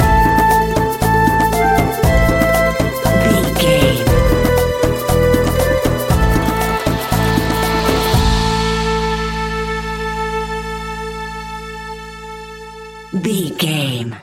Aeolian/Minor
SEAMLESS LOOPING?
World Music
percussion
congas
bongos
djembe